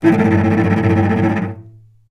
vc_trm-A2-mf.aif